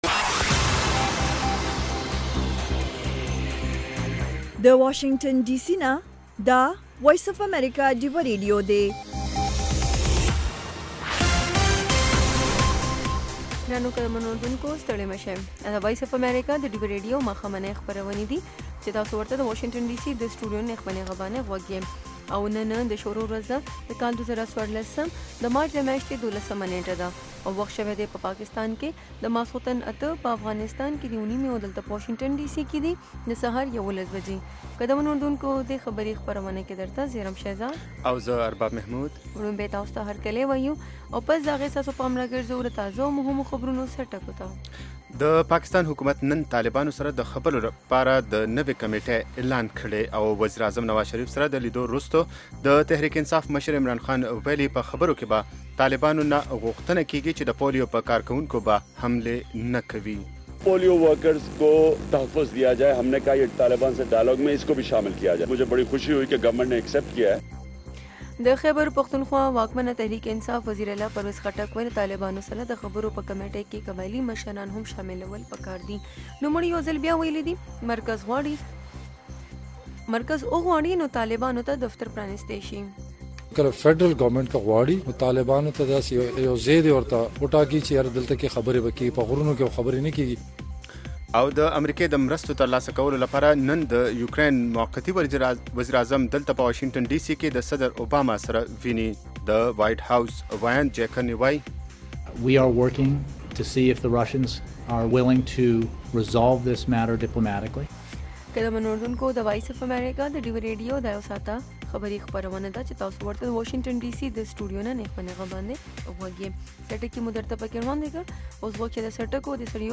خبرونه - 1500